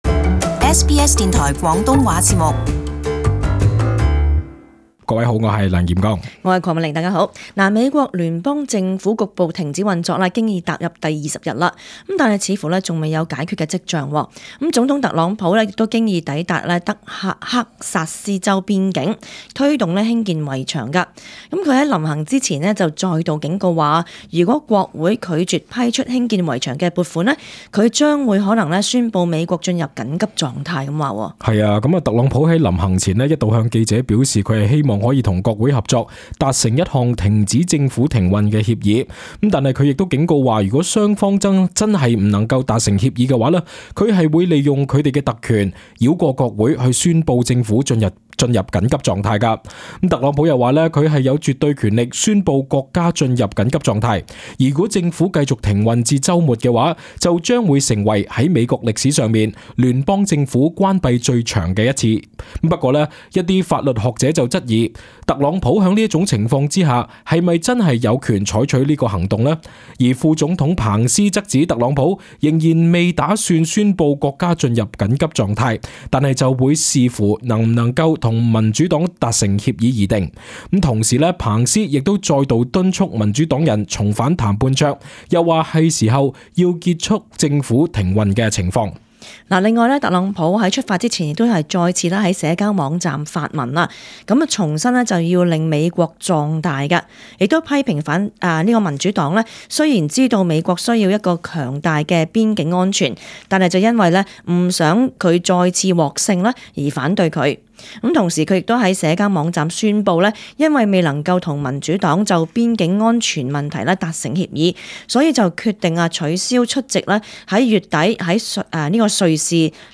Source: AAP SBS广东话播客 View Podcast Series Follow and Subscribe Apple Podcasts YouTube Spotify Download (9.65MB) Download the SBS Audio app Available on iOS and Android 美国总统特朗普在美国当地时间星期四经已抵达德克萨斯州边境，推动兴建围墙。